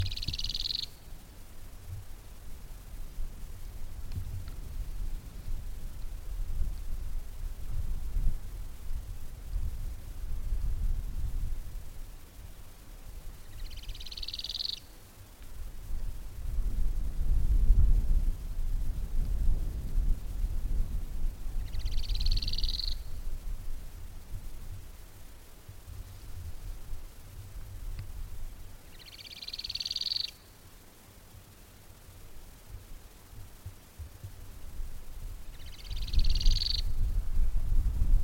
Streak-backed Canastero (Asthenes wyatti)
Life Stage: Adult
Location or protected area: Reserva Natural Villavicencio
Condition: Wild
Certainty: Photographed, Recorded vocal